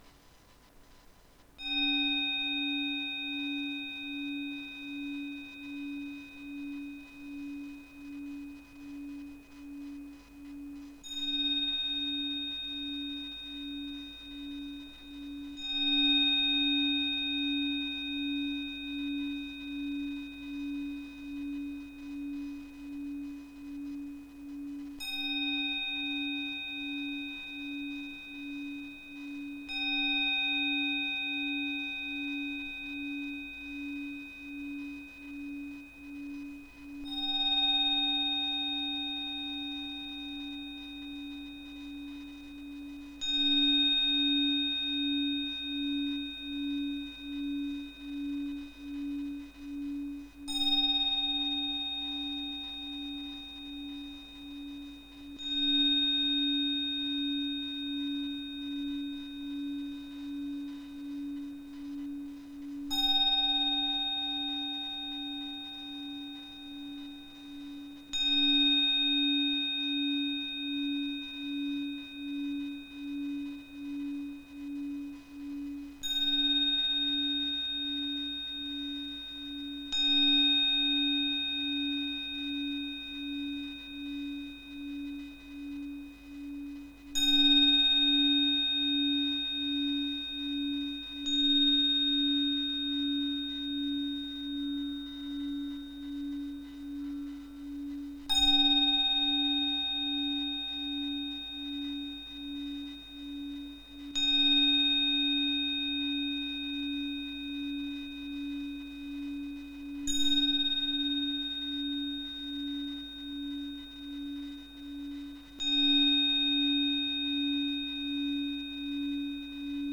Scoprite il potere trasformativo delle meditazioni di guarigione con le frequenze, una miscela armoniosa di suoni e vibrazioni progettata per allineare mente, corpo e anima.
• 285hz